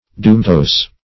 Dumetose \Du"me*tose`\